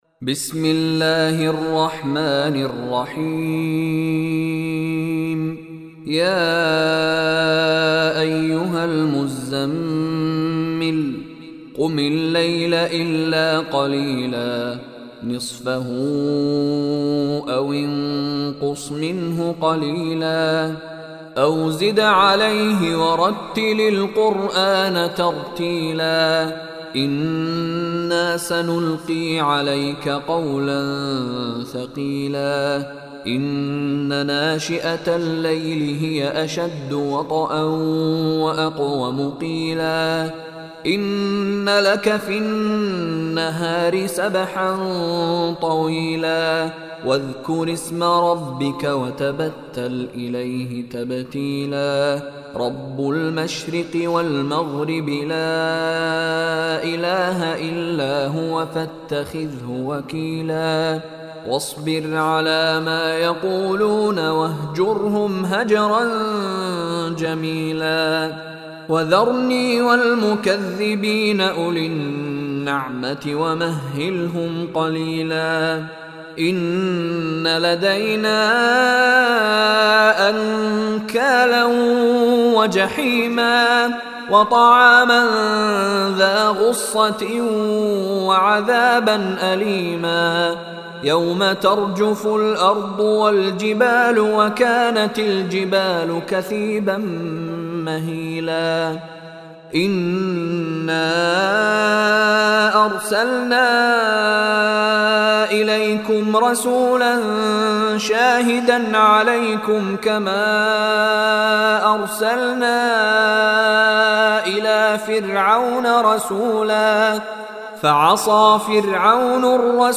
Surah Muzammil Recitation by Mishary Rashid
Surah Muzammil is seventy third chapter or surah of Holy Quran. Listen online and download mp3 tilawat / recitation of Surah Muzammil in the voice of Sheikh Mishary Rashid Alafasy.